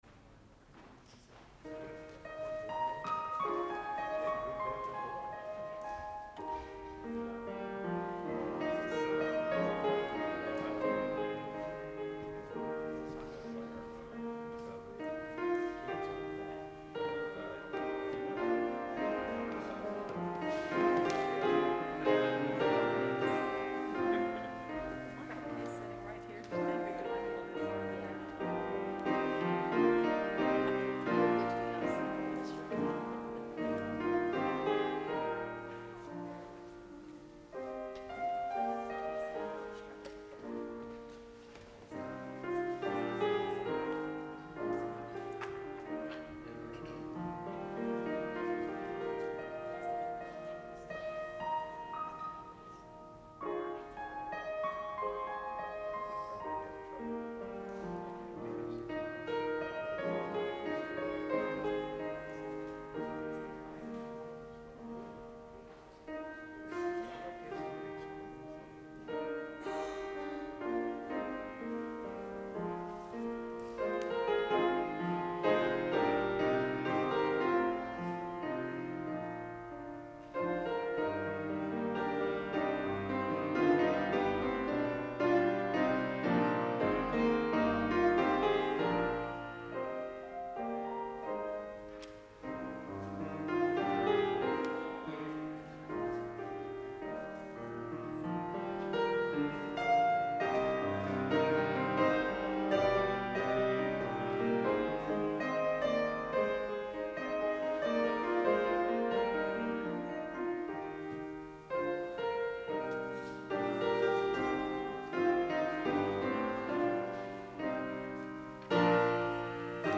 Audio worship files for CDPC 10.18.20 Service: